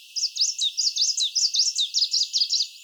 tp 10 : evolution genomes     ECE chant des oiseaux
chant Itidus